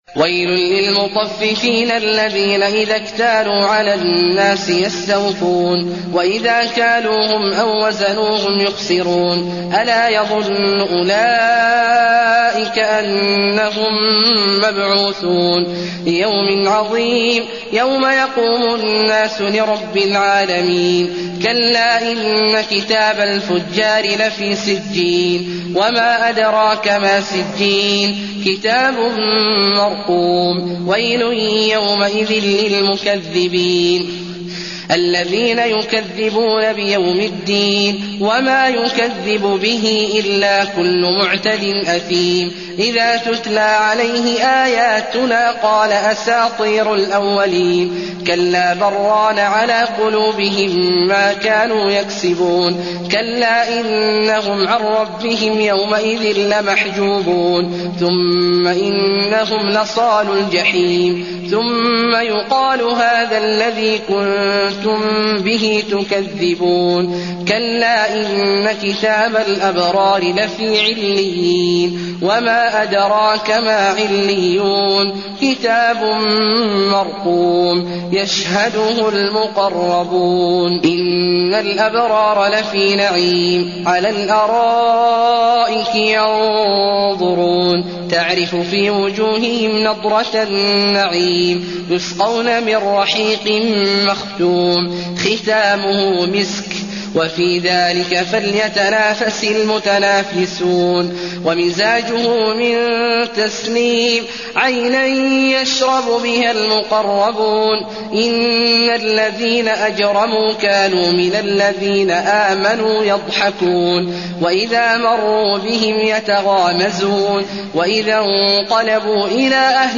المكان: المسجد النبوي المطففين